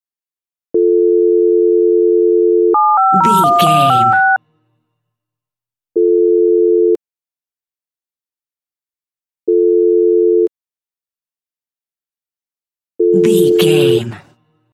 Telephone tone redial 7 numbers
Sound Effects
phone